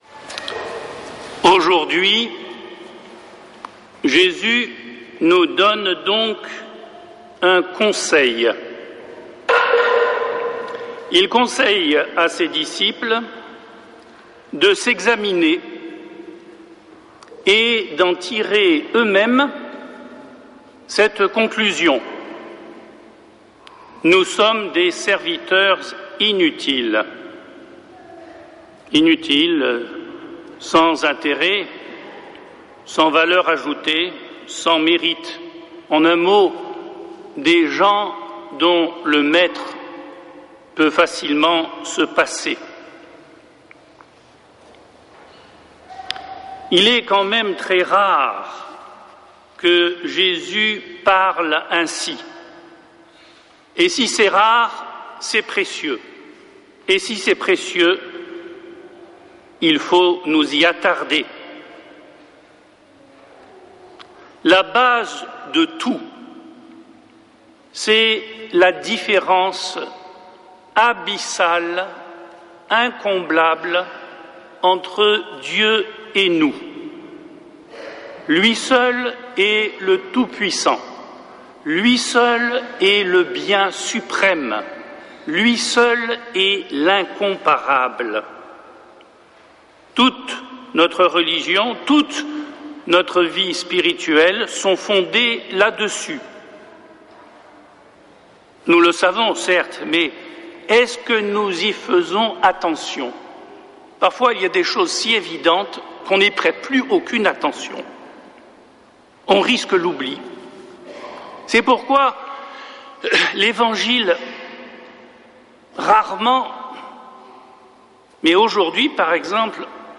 Messe depuis le couvent des Dominicains de Toulouse
homelie